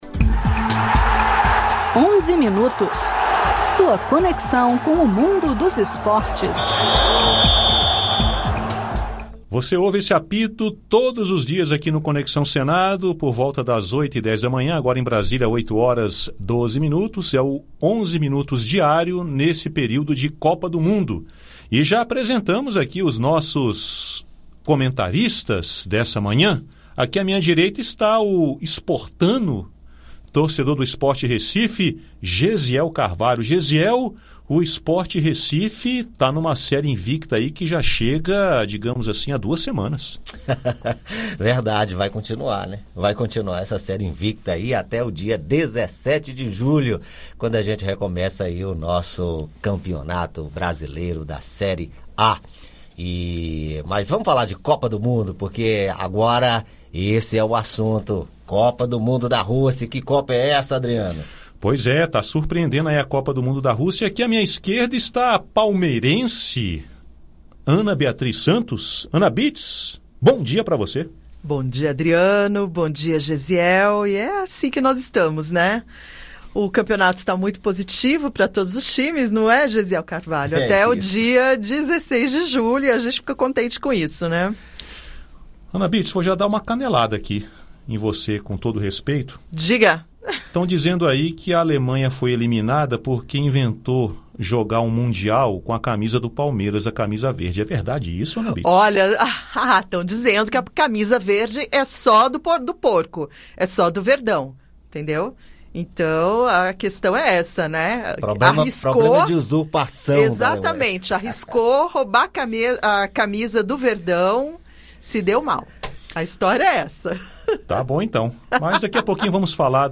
Os jornalistas da Rádio Senado comentam os resultados desta quarta-feira (27) dos jogos da Copa da Rússia com destaque para a eliminação da Alemanha pela Coreia do Sul e a classificação do Brasil em primeiro lugar na chapa. O senador Randolfe Rodrigues (REDE – AP) também comenta o resultado de 2 X 0 do Brasil sobre a Suécia.